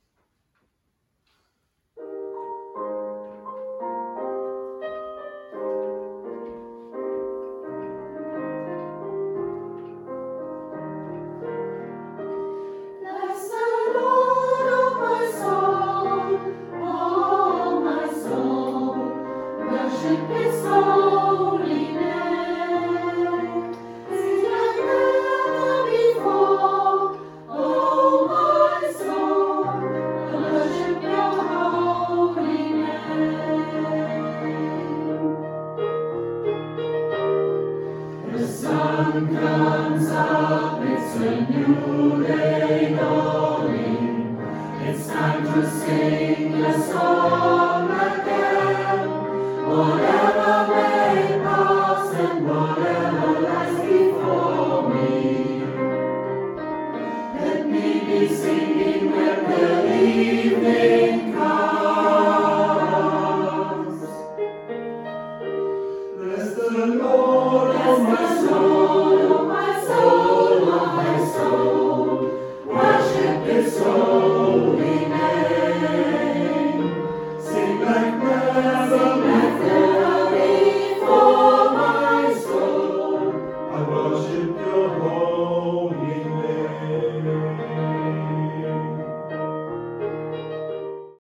Herzlich Willkommen beim Gospelchor
Die Lieder wurden während unserer Probe mit einem Handy aufgenommen und haben keine Studioqualität.